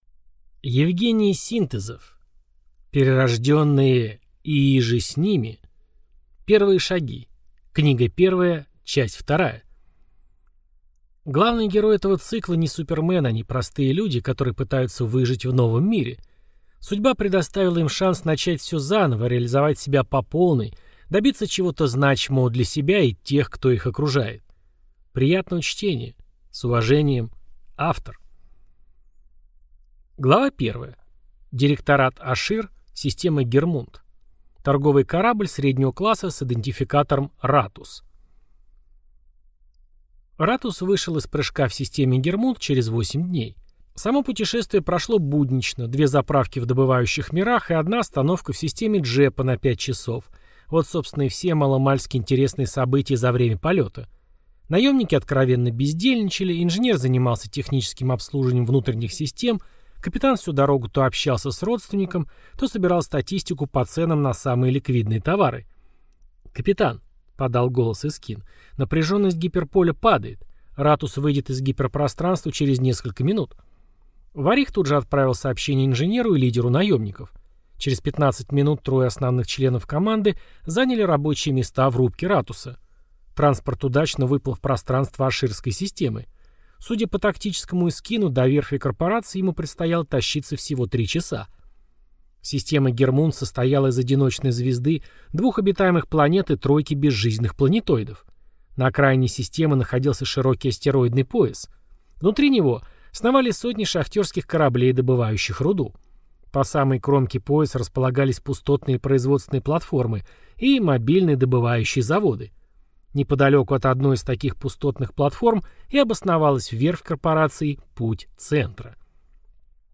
Аудиокнига Перерожденные и иже с ними…